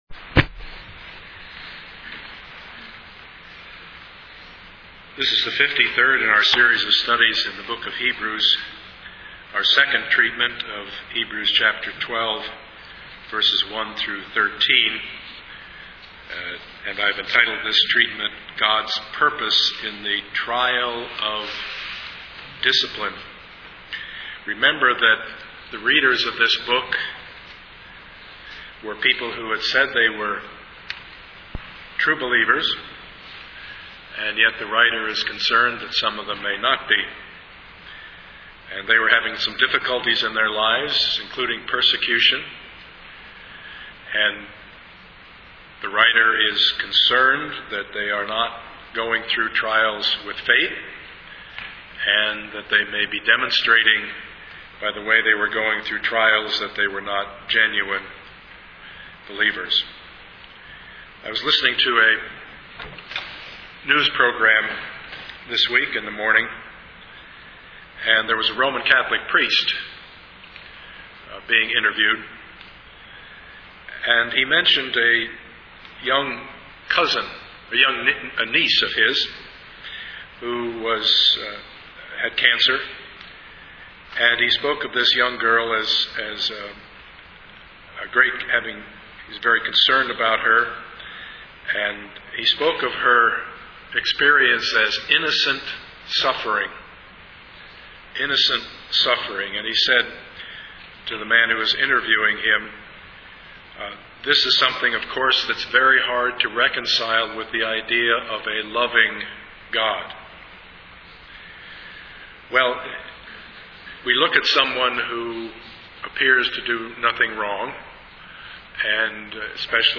Service Type: Sunday morning
Part 53 of the Sermon Series Topics: Discipline , Hebrews , Trials